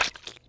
gibhit5.wav